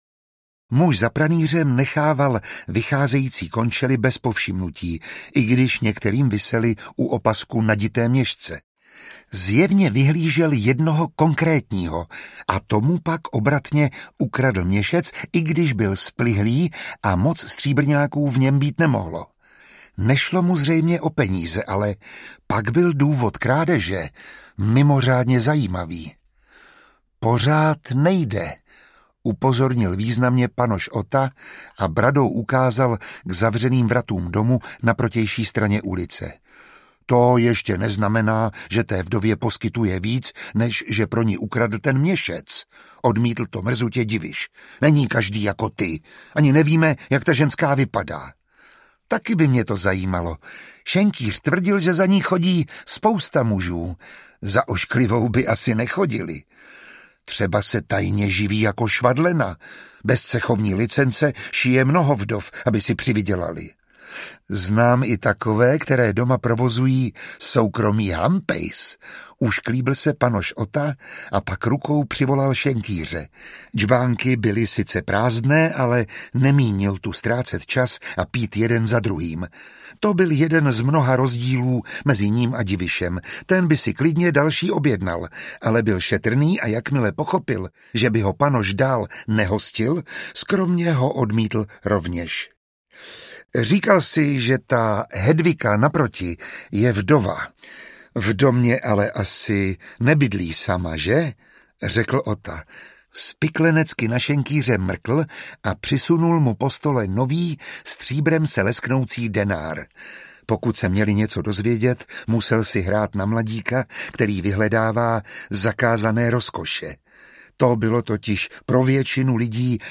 Nevěsta Kristova audiokniha
Ukázka z knihy
Čte Miroslav Táborský.
Vyrobilo studio Soundguru.